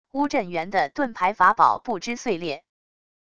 乌镇元的盾牌法宝不支碎裂wav音频